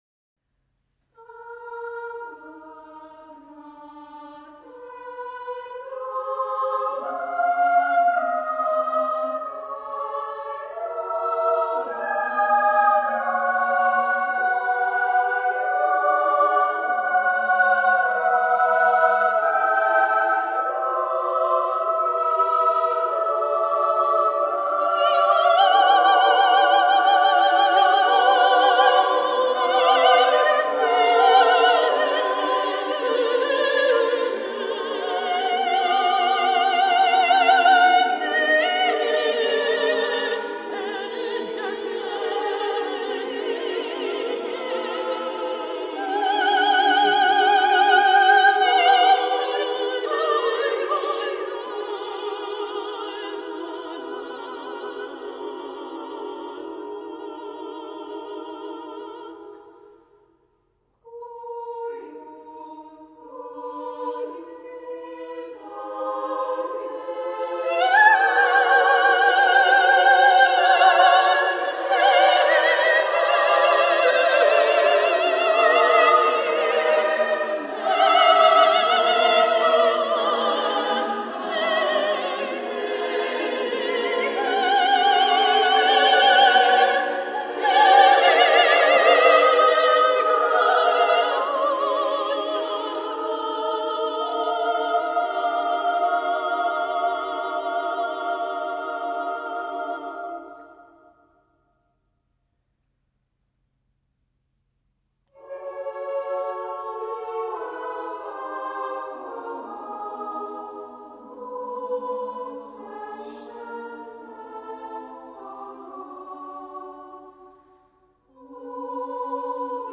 Genre-Style-Forme : contemporain ; Variations ; Sacré
Type de choeur : SSSAAA  (6 voix égales de femmes )
Tonalité : tonal